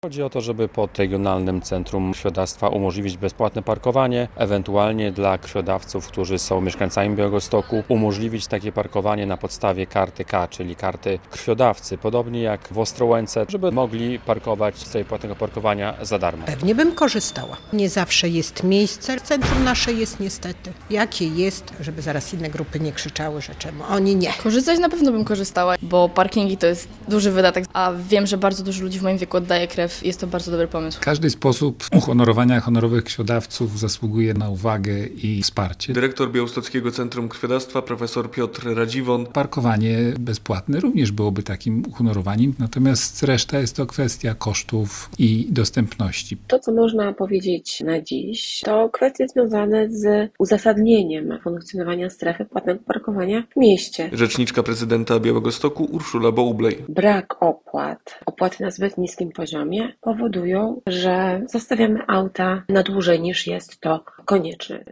Czy będą za darmo parkować w Białymstoku? - relacja